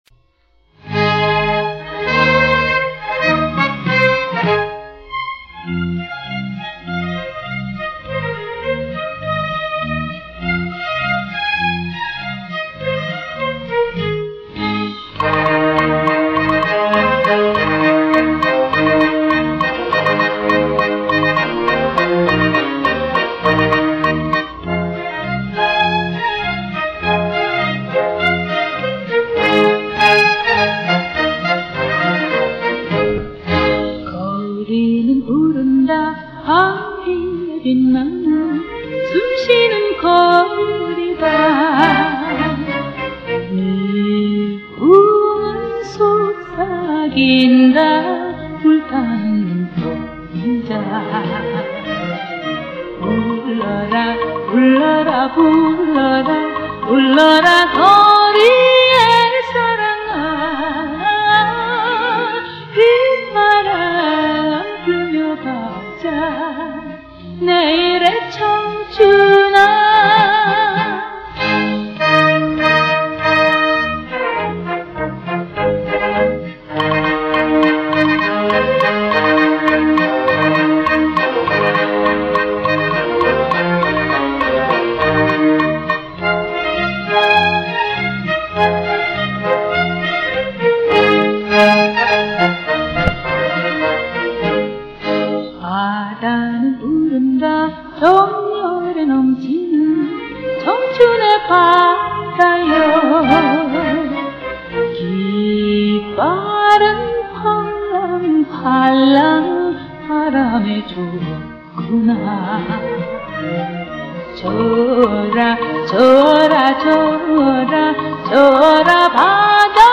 이 음원 음반구입 복각한 음원